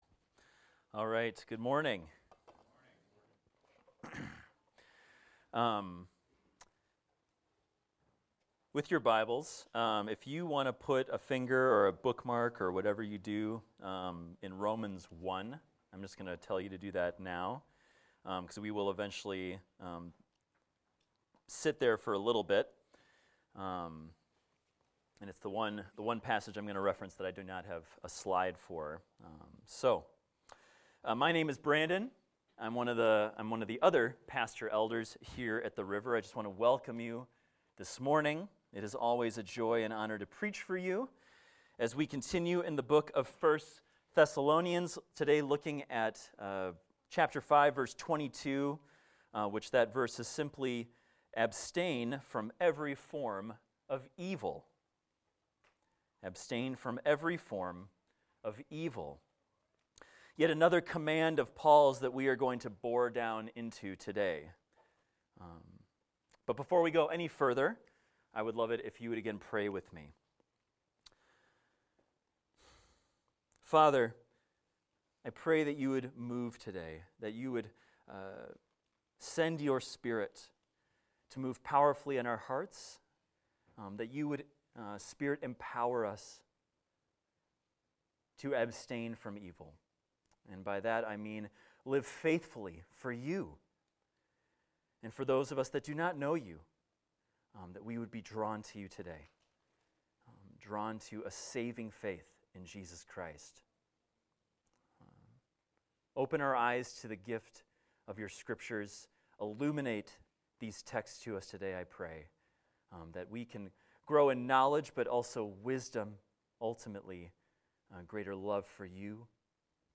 This is a recording of a sermon titled, "Abstain From Evil."